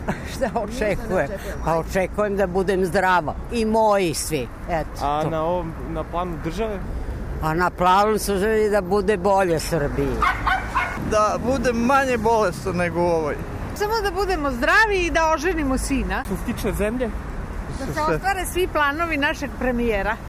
Na pitanje šta očekuju od naredne godine, građani sa kojima smo razgovarali na ulicama Beograda odgovaraju različito, u zavisnosti od uzrasta.
Dok stariji imaju nešto drugačije želje: